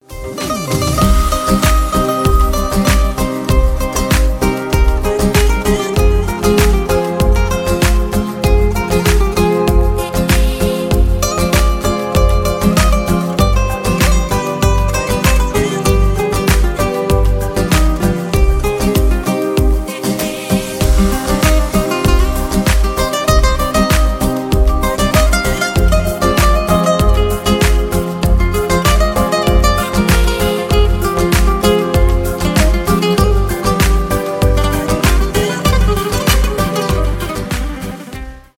гитара , без слов , инструментальные